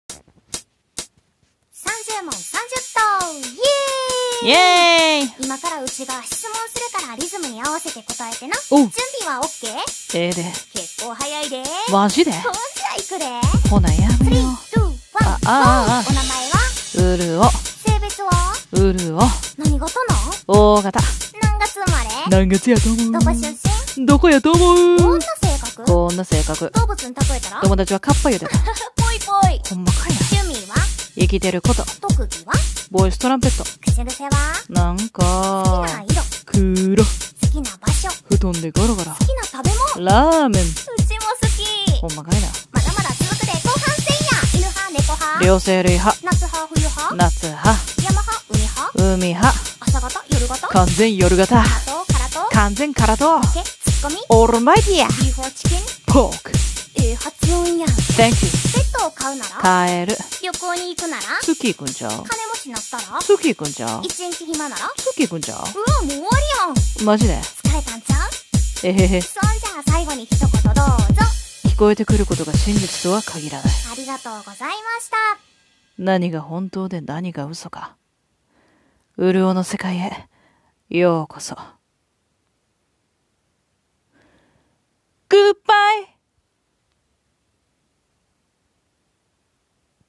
30問30答 関西弁で質問するで！